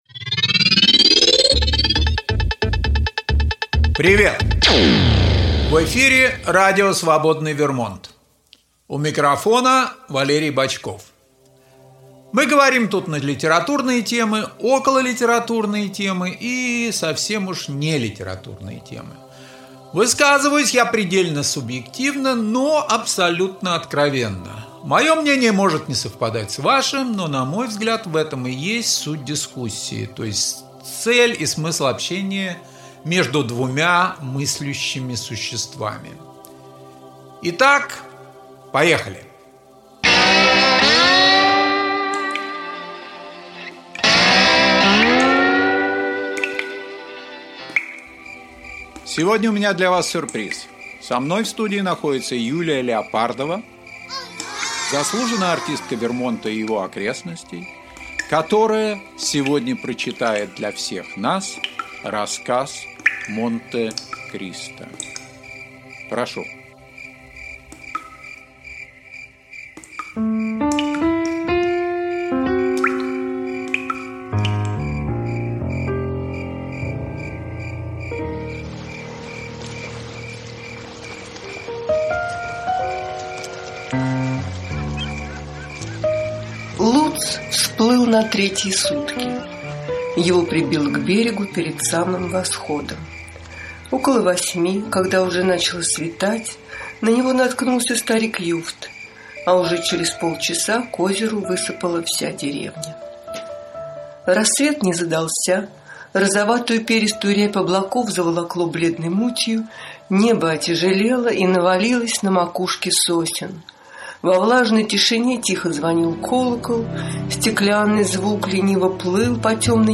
Аудиокнига Монтекристо | Библиотека аудиокниг